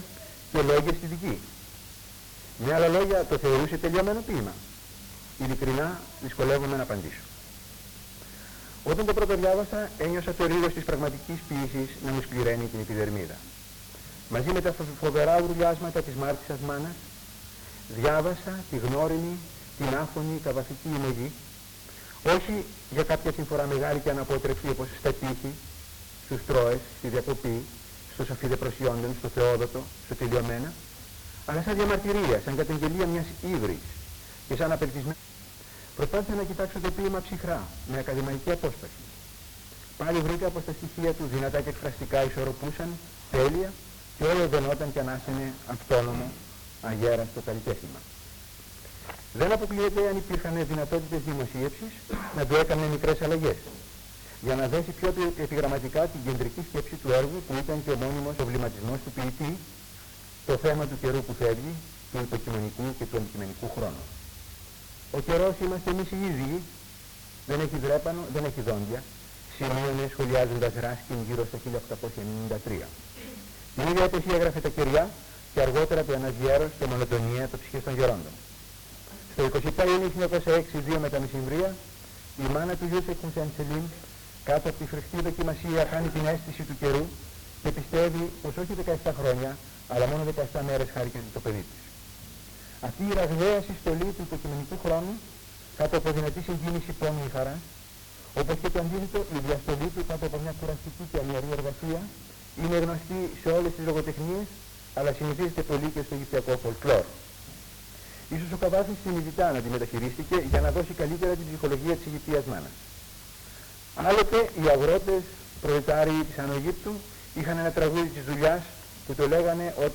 Εξειδίκευση τύπου : Εκδήλωση
Εμφανίζεται στις Ομάδες Τεκμηρίων:Εκδηλώσεις λόγου